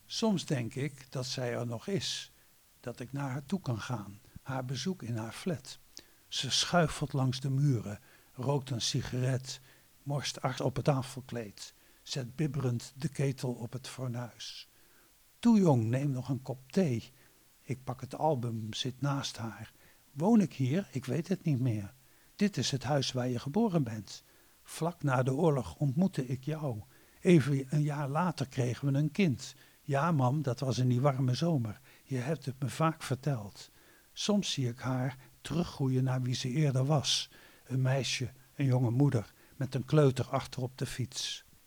Gedicht "Moederschoot"